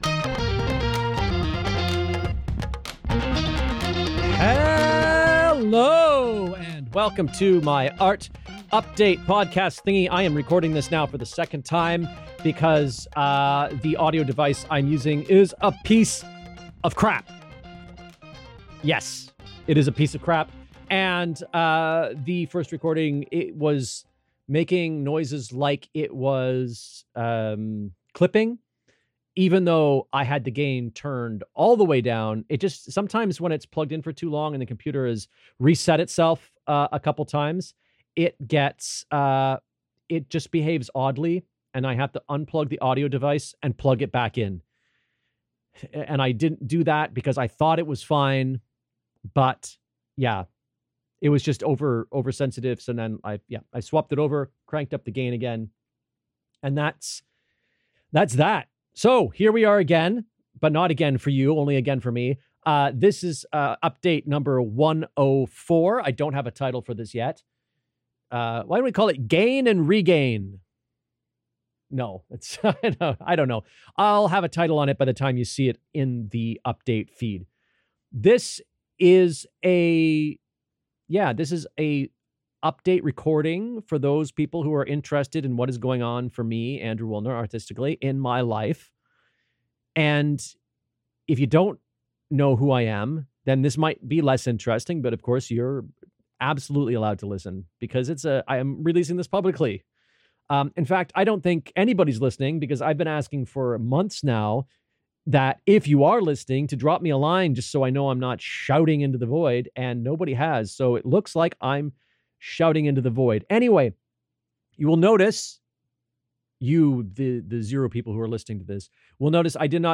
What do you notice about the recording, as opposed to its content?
No time record last week, so I quickly rushed this recording on Monday, doing some basic updates on Interference, Anxiety, and my life.